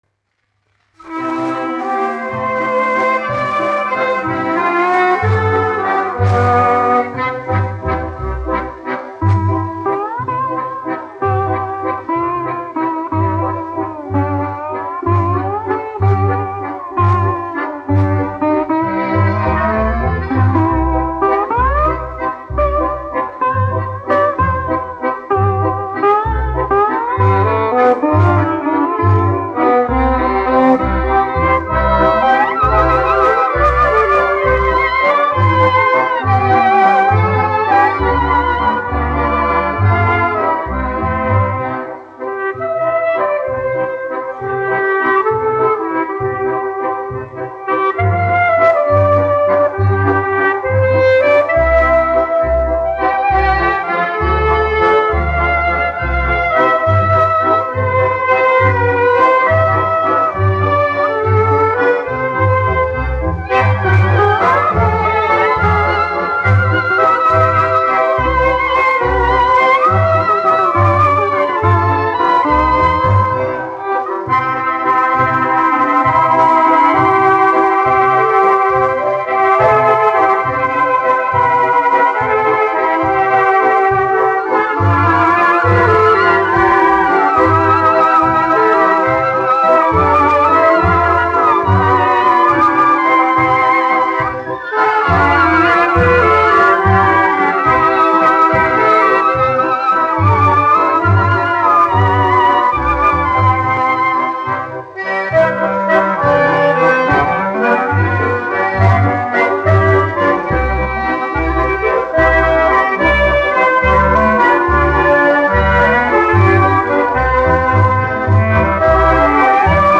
1 skpl. : analogs, 78 apgr/min, mono ; 25 cm
Valši
Populārā instrumentālā mūzika
Skaņuplate